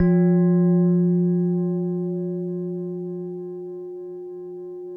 WHINE  F1 -L.wav